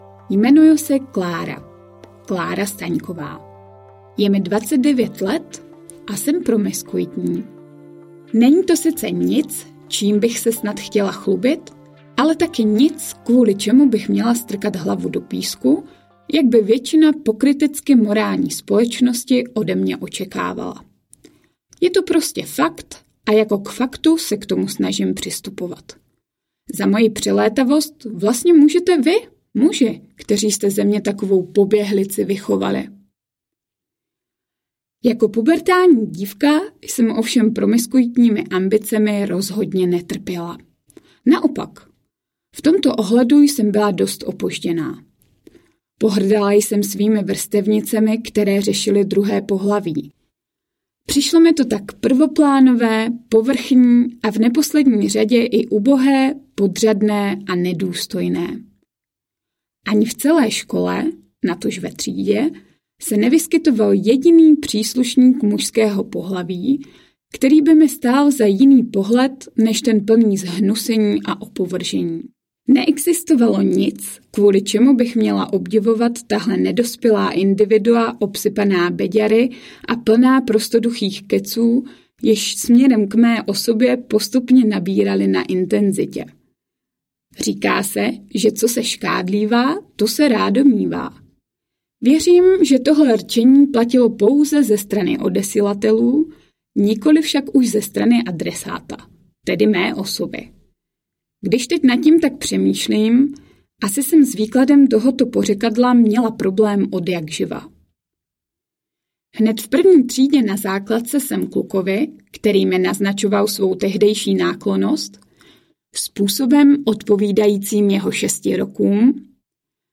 Muži, kteří mě zkazili audiokniha
Ukázka z knihy
muzi-kteri-me-zkazili-audiokniha